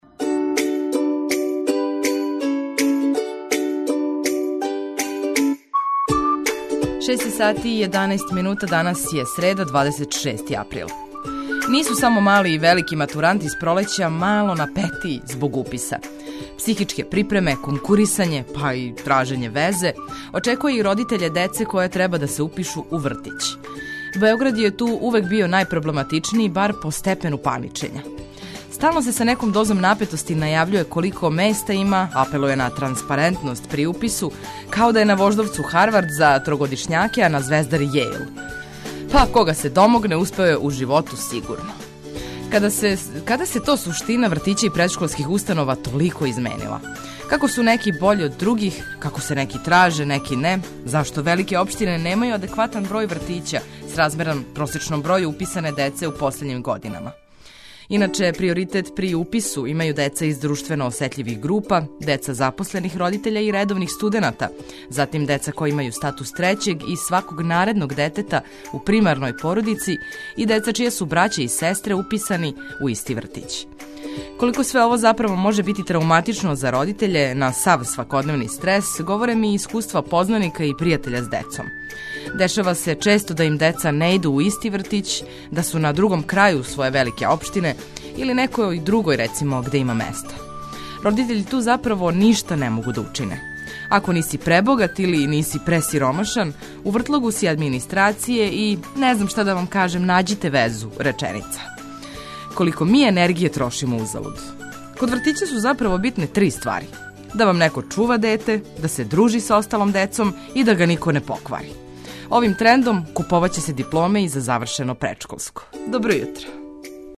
Прве сате јутра испунили смо музиком за разбуђивање и информацијама које су важне.